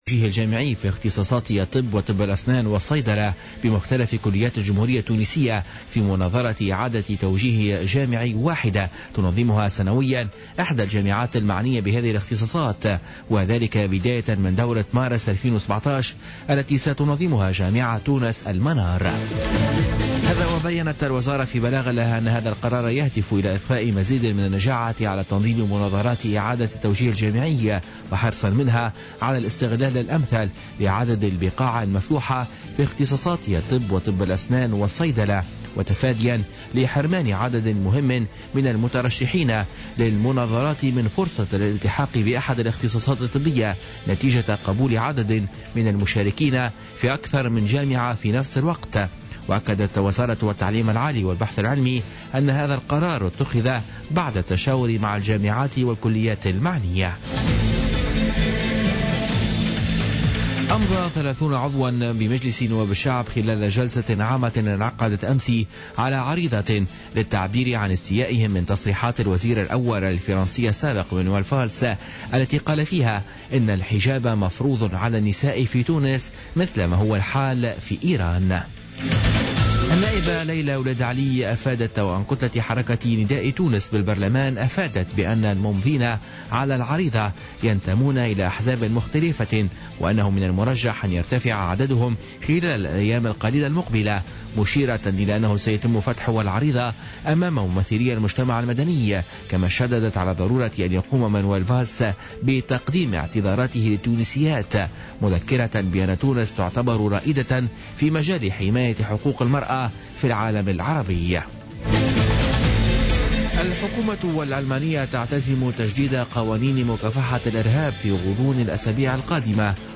نشرة أخبار منتصف الليل ليوم الاربعاء 11 جانفي 2017